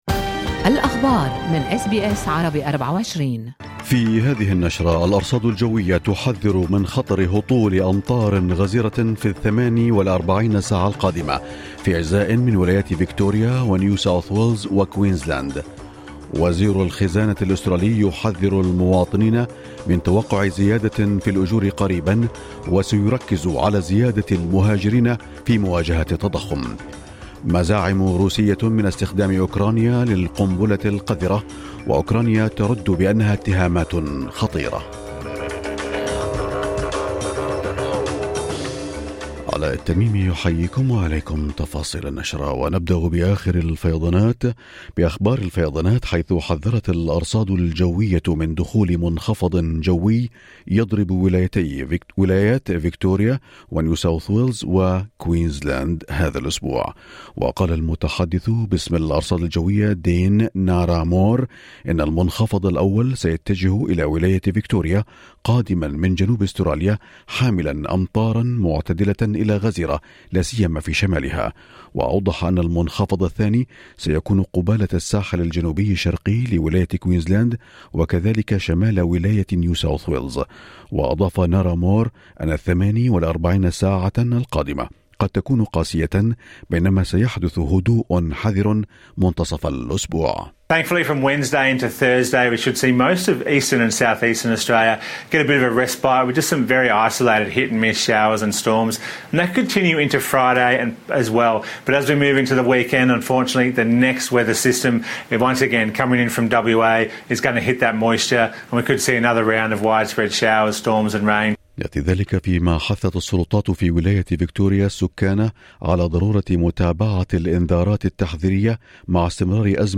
نشرة أخبار الصباح 24/10/2022